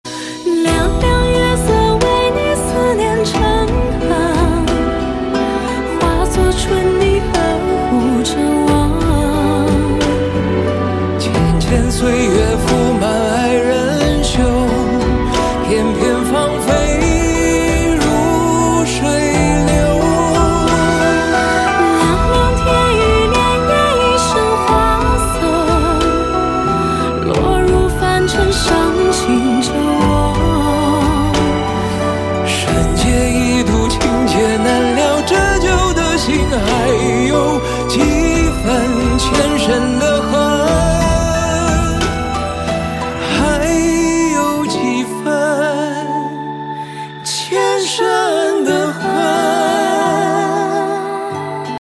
Thể loại nhạc chuông: Nhạc trung hoa